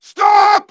synthetic-wakewords
synthetic-wakewords / stop /ovos-tts-plugin-deepponies_Heavy_en.wav
ovos-tts-plugin-deepponies_Heavy_en.wav